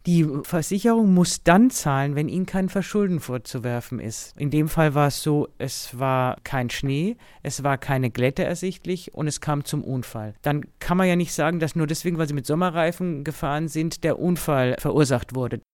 Auto, DAV, O-Töne / Radiobeiträge, Ratgeber, Recht, , , , , ,